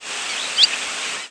Cave Swallow Petrochelidon pelodoma
Flight call description A descending call (a pure-toned "psuer" or a more husky "nhew") and a soft, rising call ("swheet" or "nhwit").
Rising  ("swheet") call  from bird of West Indian population (P. p. fulva group) in flight.